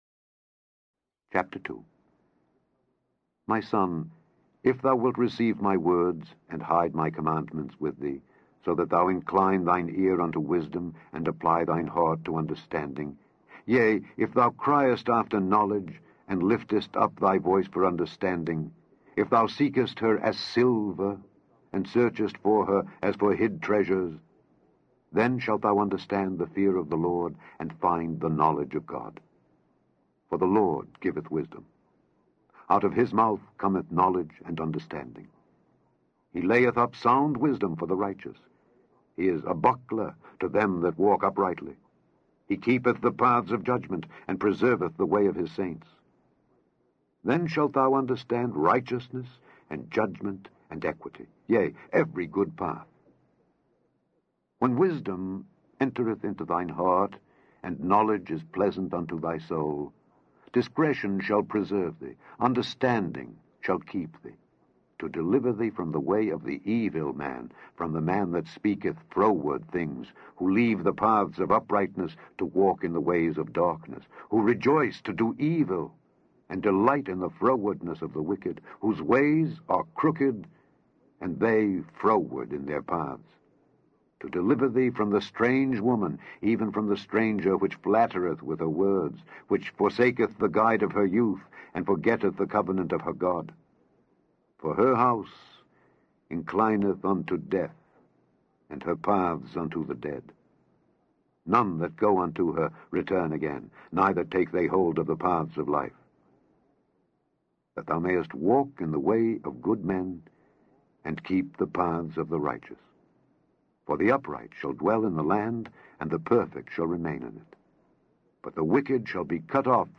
Daily Bible Reading: Proverbs 2-3
In this podcast, you can listen to Alexander Scourby read to you Proverbs 2-3.